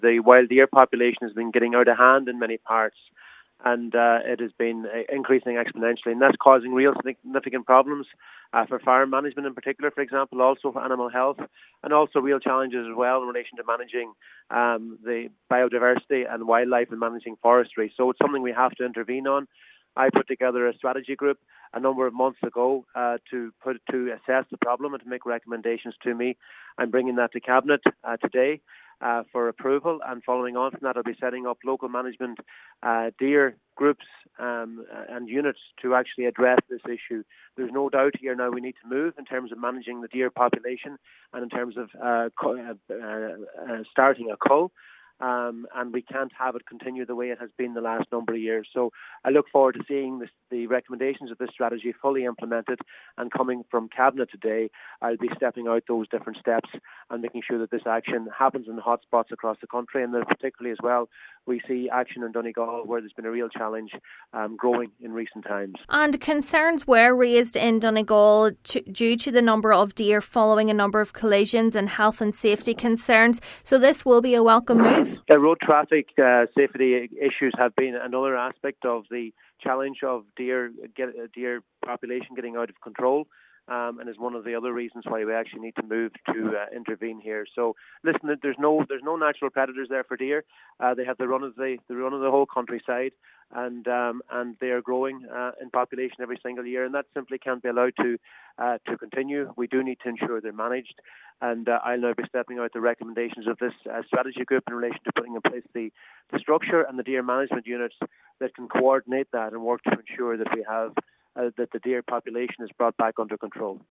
Agriculture Minister Charlie McConalogue will bring the report before Cabinet today.
He says it has been particularly challenging for Donegal: